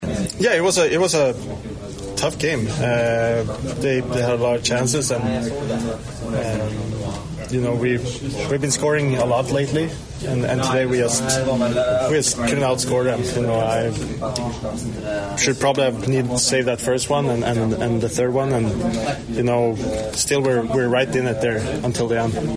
Goaltender Filip Gustavsson recaps the loss.